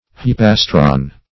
Search Result for " hyopastron" : The Collaborative International Dictionary of English v.0.48: Hyopastron \Hy`o*pas"tron\, n. [Hyo- + plastron.]